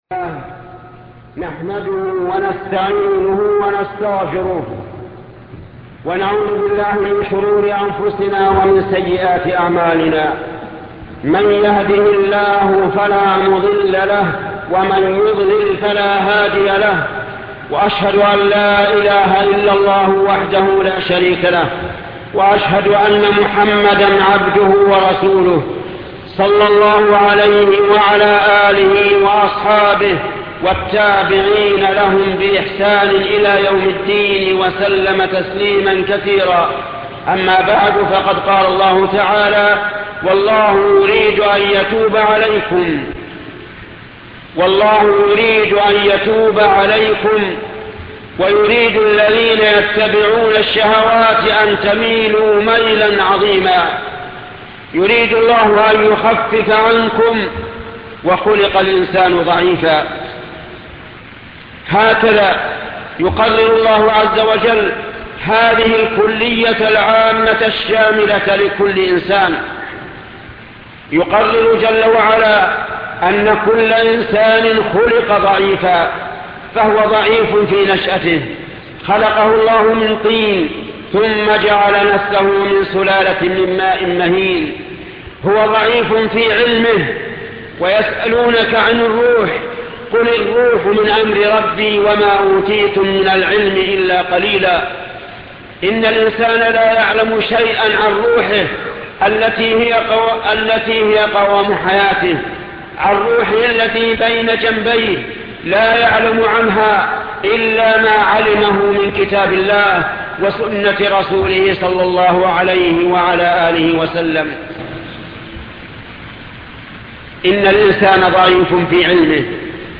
خطبة فقة المعاملات وحكم التأمين الشيخ محمد بن صالح العثيمين